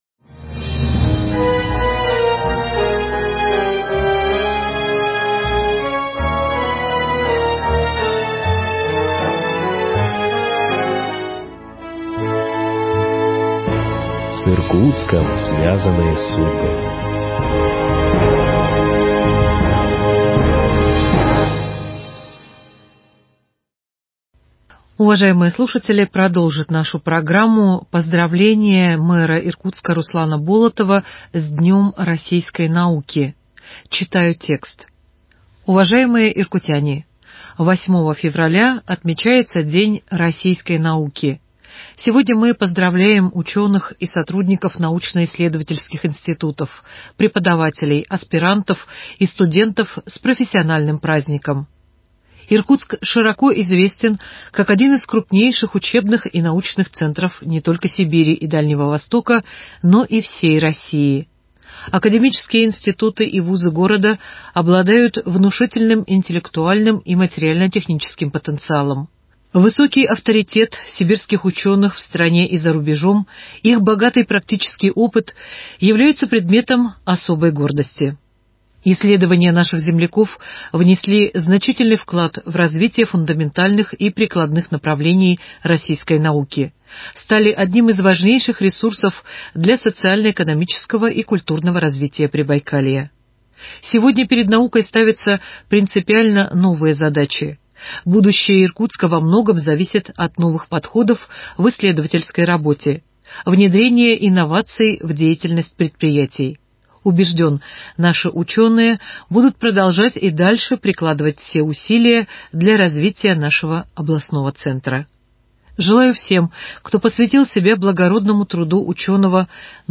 Поздравление мэра г. Иркутска Руслана Болотова с Днем российской науки.